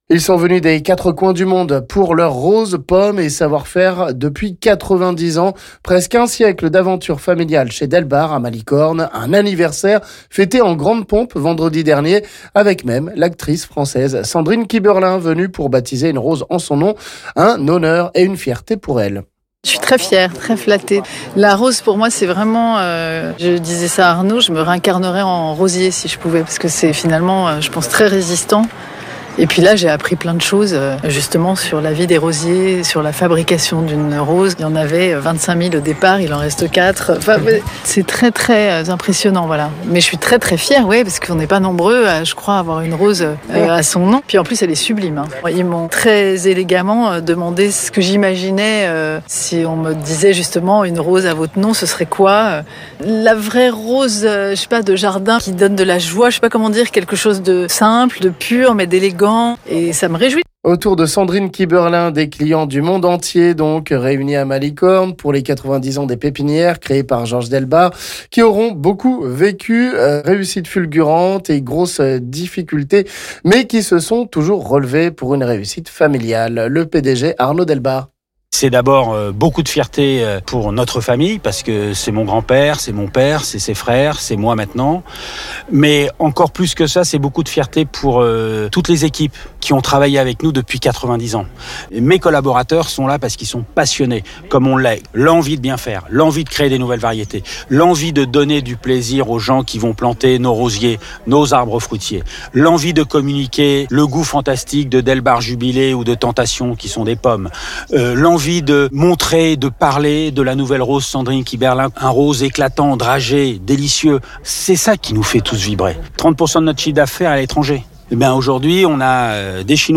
Près d’un siècle d’une aventure familiale incroyable, en inventant régulièrement des nouvelles variétés de roses et de pommes notamment, pour arriver aujourd’hui à une certaine excellence qui plait à des clients du monde entier…Les pépinières et roseraies Georges Delbard ont fêté leurs 90 ans vendredi, avec pour l’occasion, l’actrice Sandrine Kiberlain qui est venue baptiser une rose à son nom.